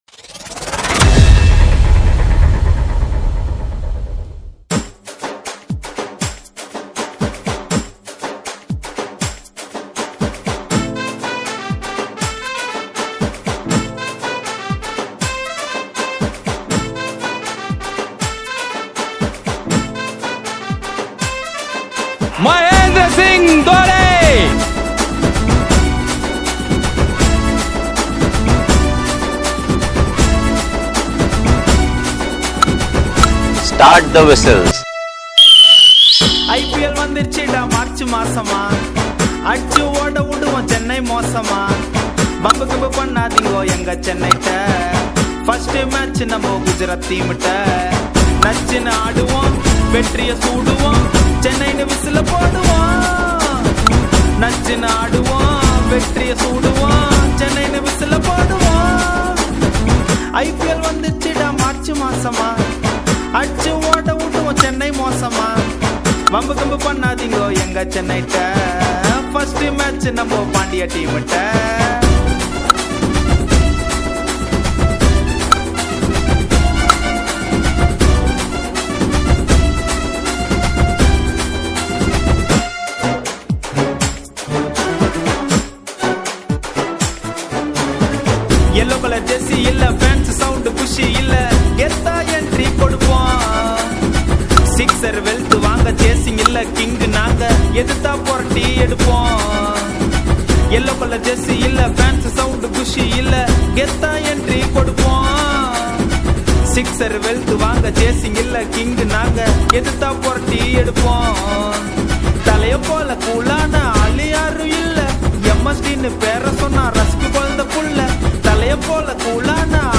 CSK IPL DJ REMIX